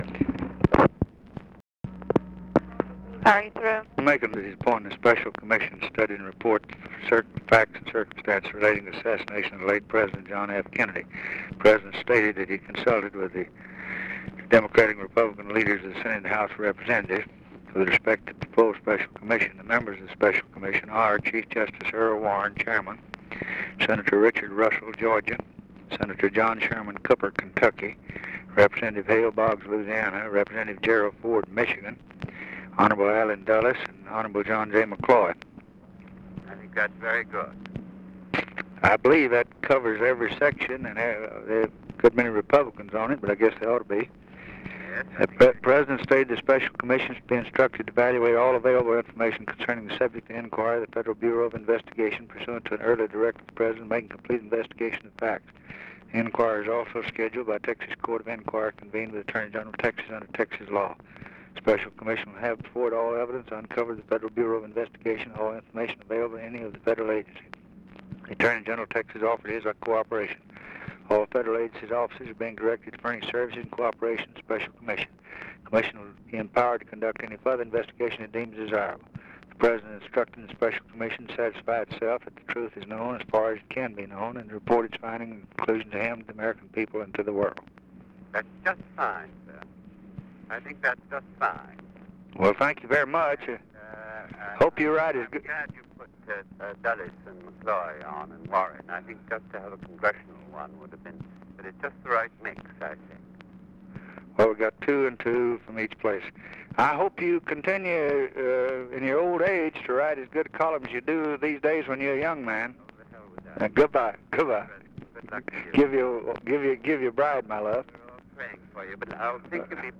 Conversation with JOSEPH ALSOP, November 29, 1963